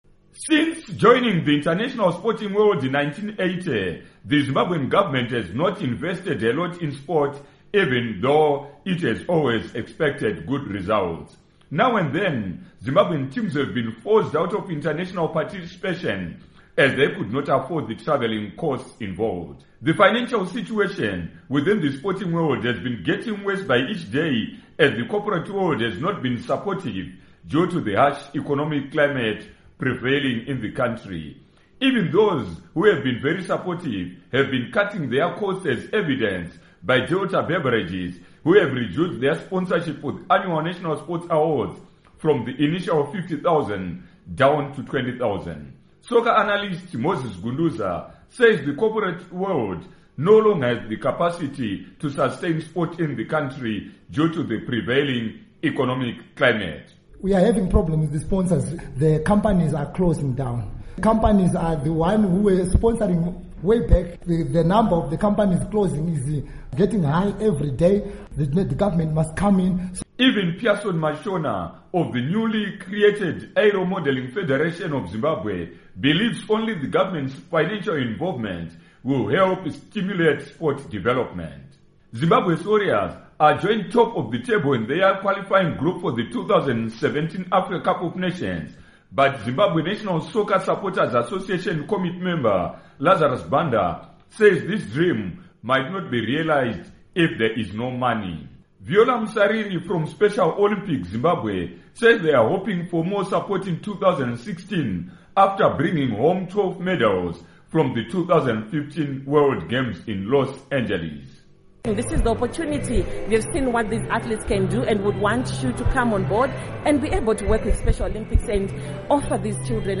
Report on Zimbabwe Sports